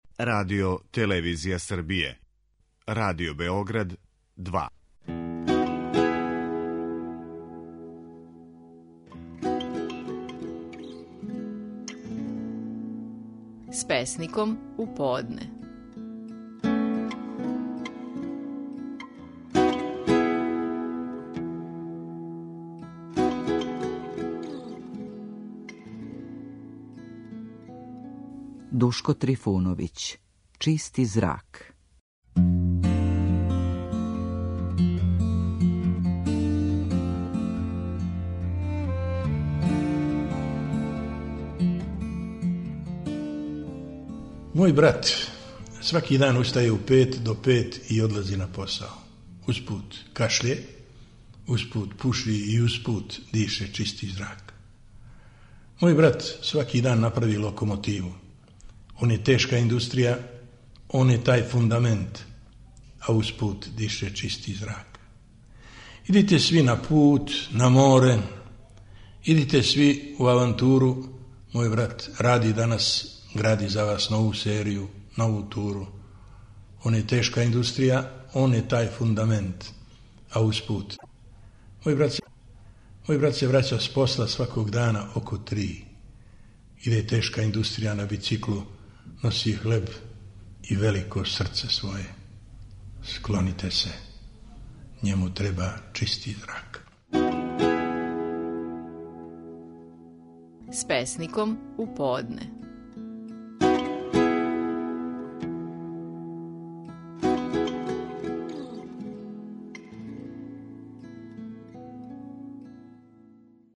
Наши најпознатији песници говоре своје стихове
У данашњој емисији, слушаћемо како је Душко Трифуновић говорио своју песму Чисти зрак.